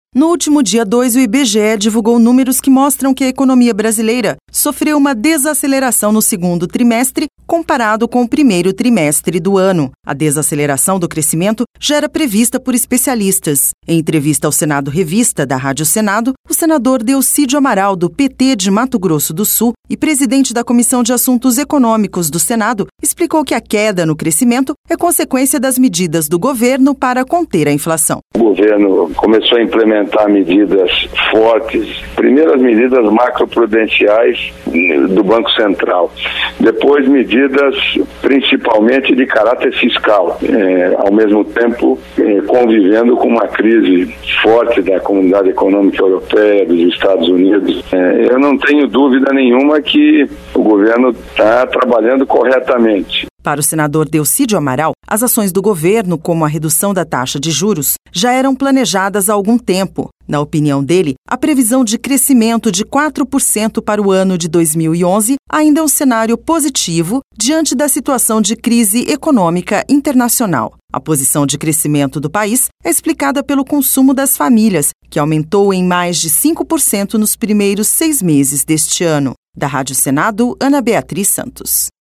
A desaceleração do crescimento já era prevista por especialistas. Em entrevista ao Senado Revista, da Rádio Senado, o senador Delcídio Amaral, do PT de Mato Grosso, e presidente da Comissão de Assuntos Econômicos do Senado, explicou que a queda no crescimento é conseqüência das medidas do governo para conter a inflação.